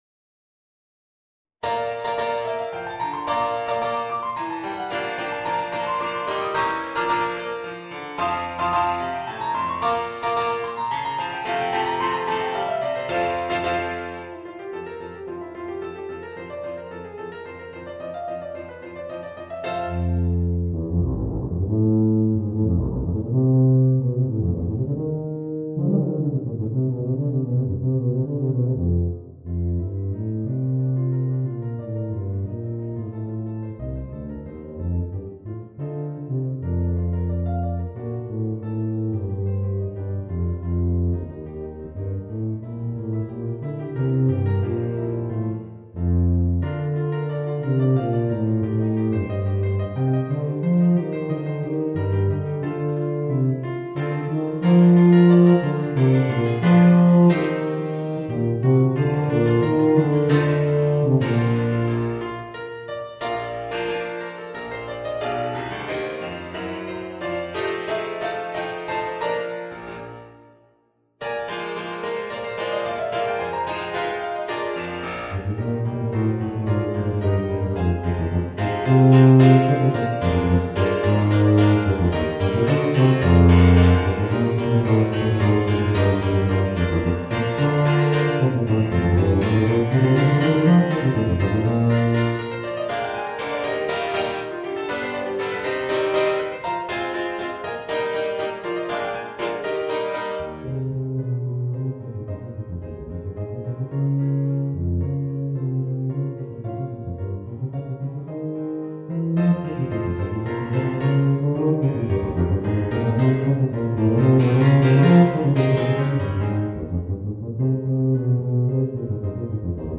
Voicing: Tuba and Piano